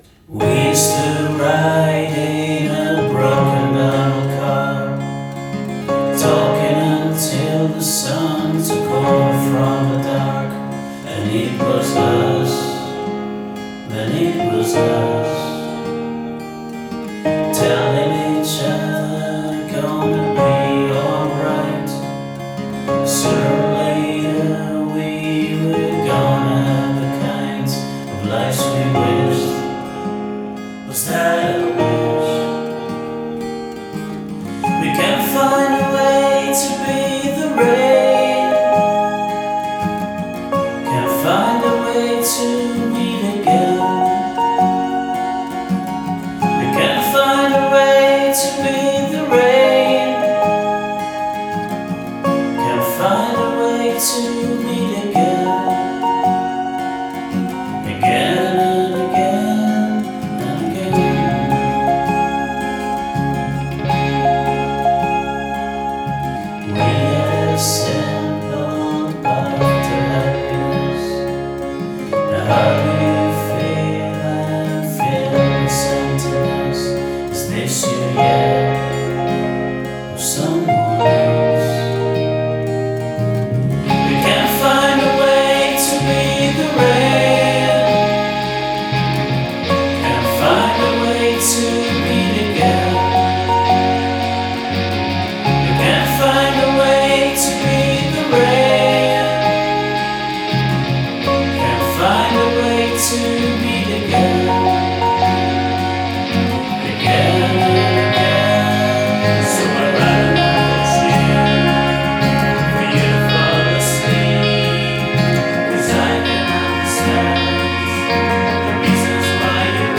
vocals, guitar, bass, drums, keyboards